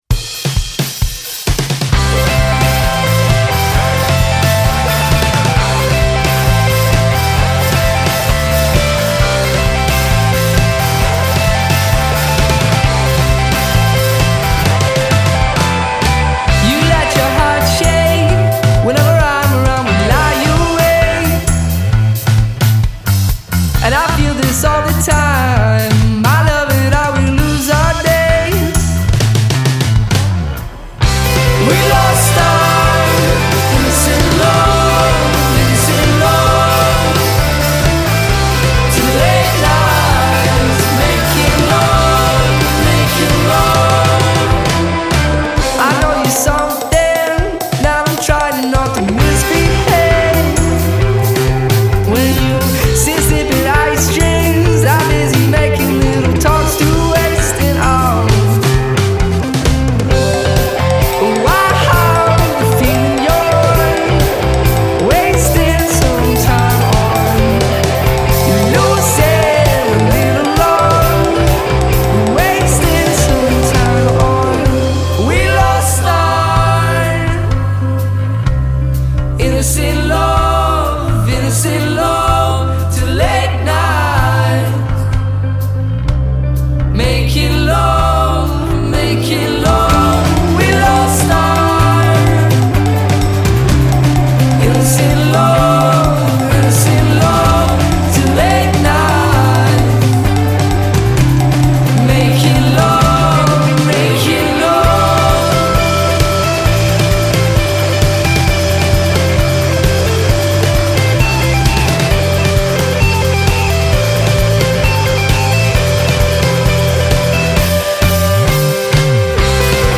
They say “Fun and fresh pop music.